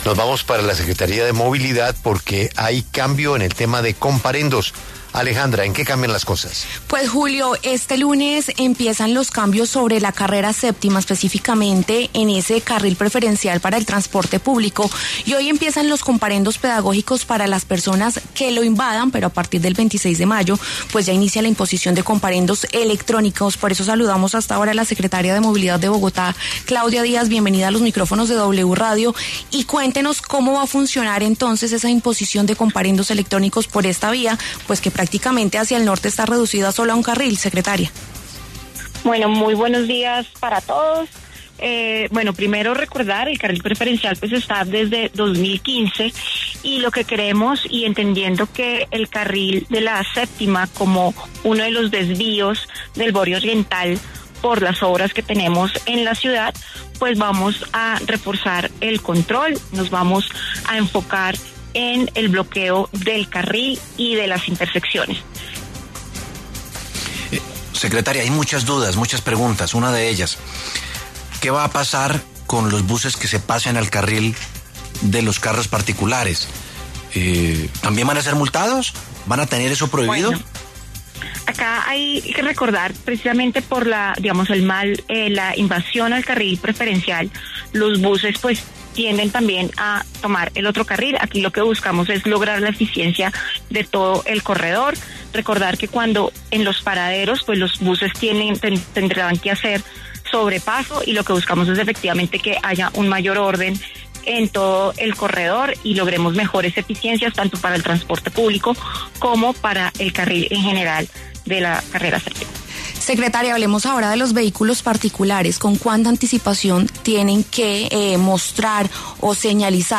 La secretaria de Movilidad de Bogotá, Claudia Díaz, explicó en los micrófonos de W Radio que las medidas se toman para darle eficiencia al servicio público que recorre esta vía.